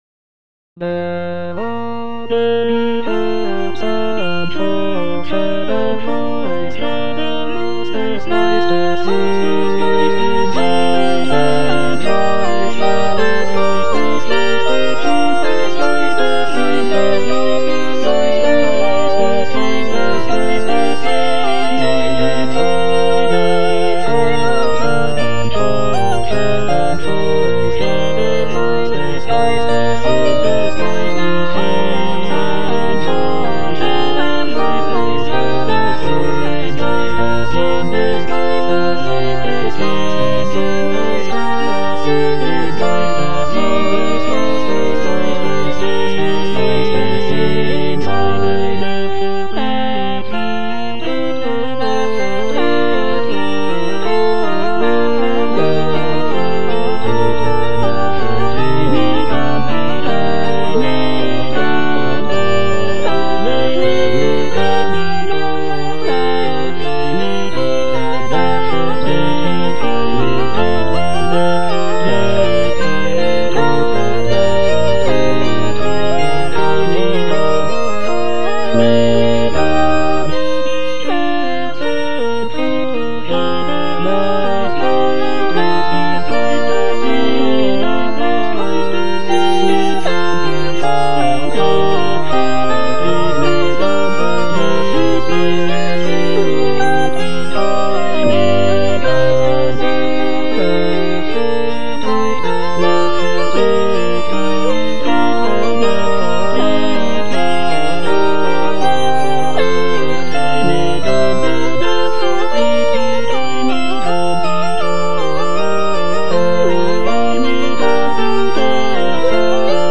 J.S. BACH - DER GEIST HILFT UNSER SCHWACHHEIT AUF BWV226 Der aber die Herzen forschet (All voices) Ads stop: auto-stop
This work is a choral setting of the biblical text from Romans 8:26-27. It is structured in seven parts for double choir, showcasing Bach's mastery of counterpoint and harmonic complexity.